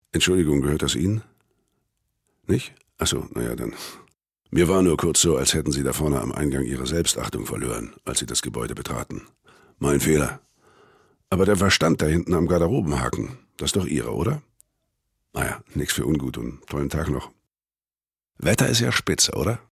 dunkel, sonor, souverän
Mittel plus (35-65)
Lip-Sync (Synchron)